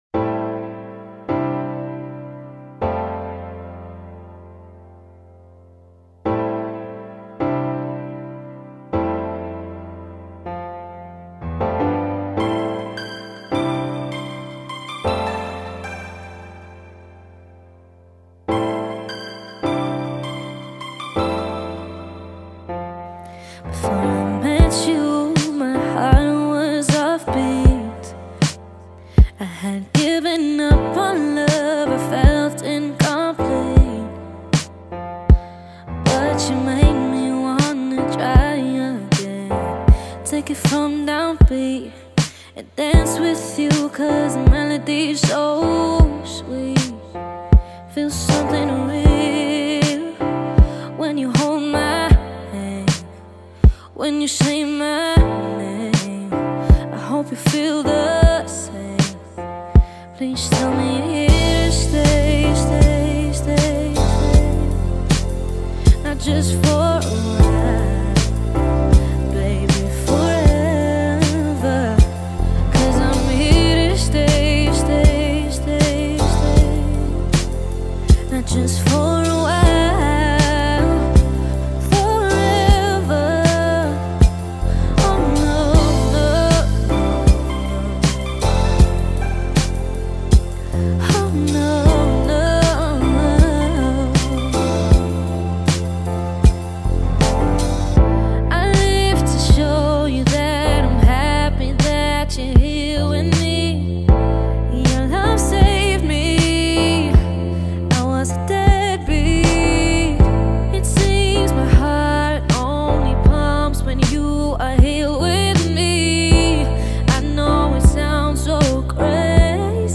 S African singer
R&B track